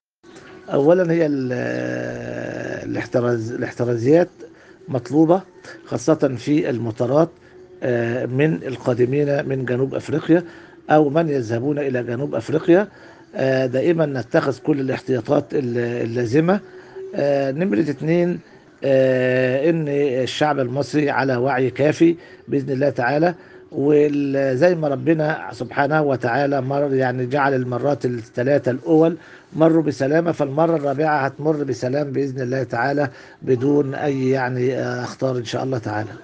الحوار الصحفي التالي